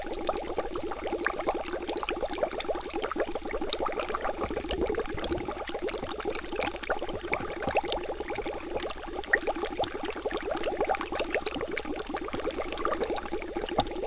Alchemy-Bubbles-01.ogg